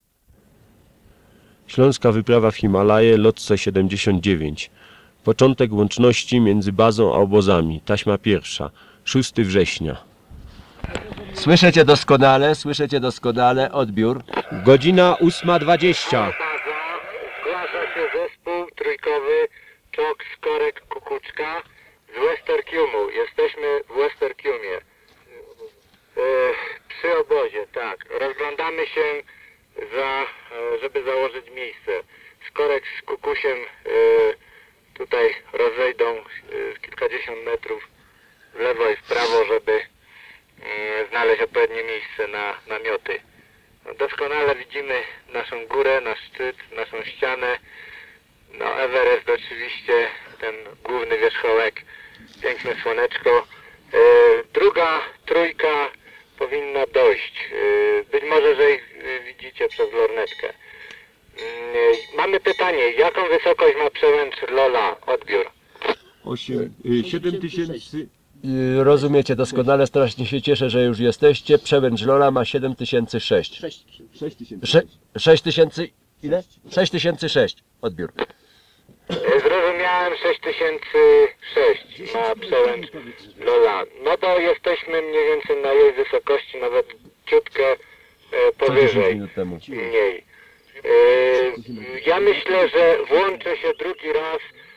Nagrania łączności radiowej z wypraw wysokogórskich – audialna podróż w przeszłość
Kasety magnetofonowe w pudełkach ochronnych
Fragment 1 – łączność radiowa z Andrzejem Czokiem (Lhotse 1979)